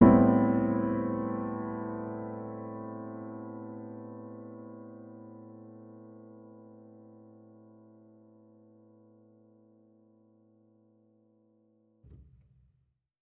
Index of /musicradar/jazz-keys-samples/Chord Hits/Acoustic Piano 2
JK_AcPiano2_Chord-Am9.wav